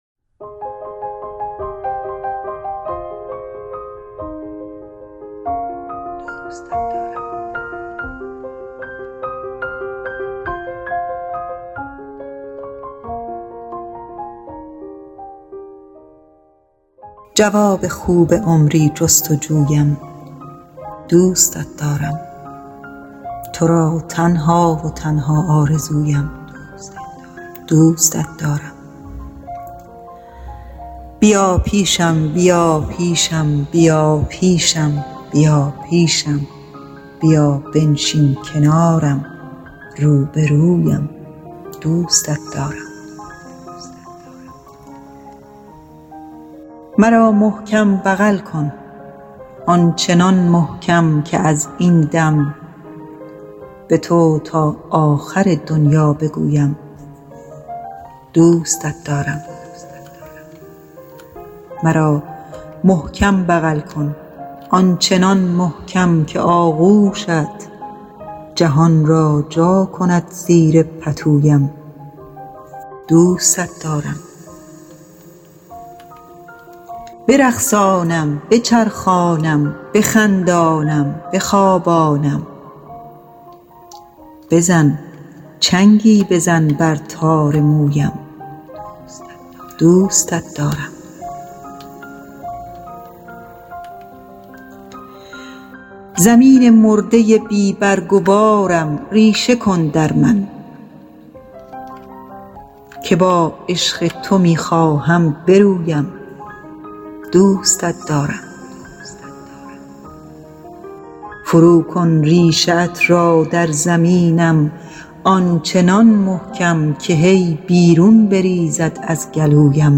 دکلمه و شعر عاشقانه